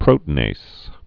(prōtn-ās, -āz, prōtē-nās, -nāz)